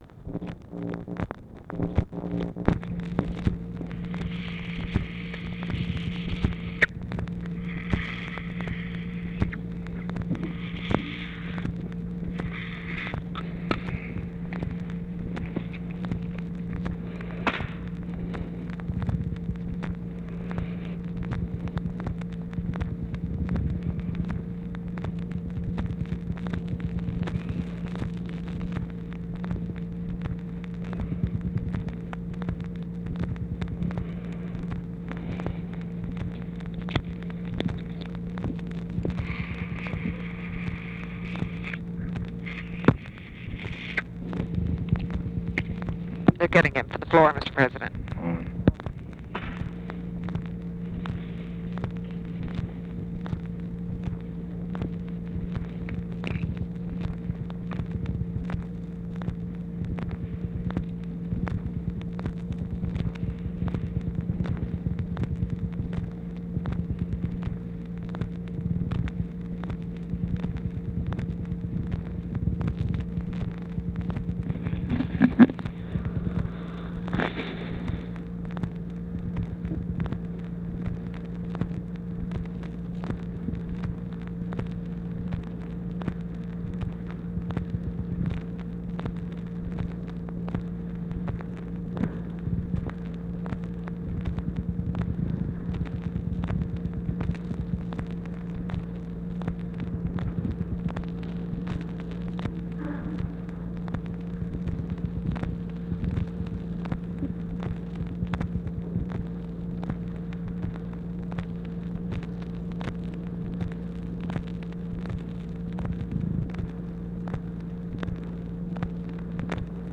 Conversation with JOHN MCCORMACK, June 9, 1964
Secret White House Tapes